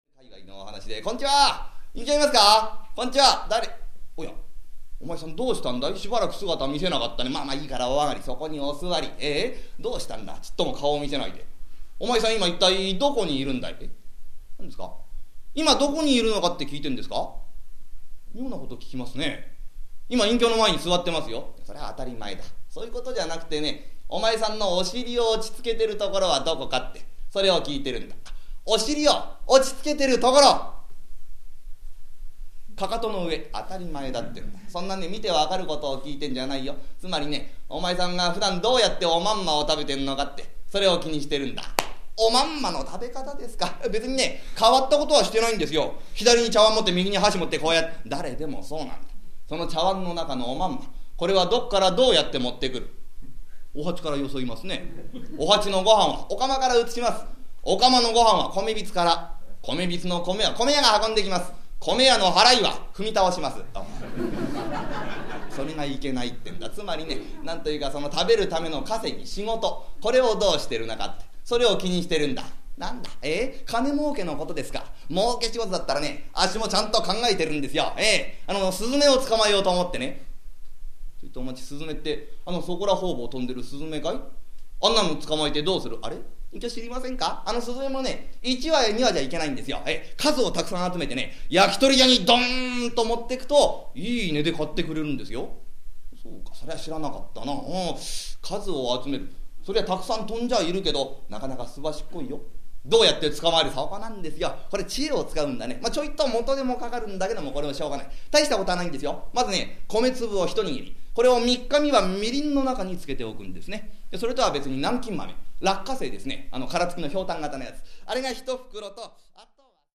「さーぎぃー」の声のすばらしさに聞きほれていただきたい一席です。